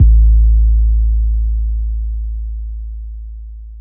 MB 808 (26).wav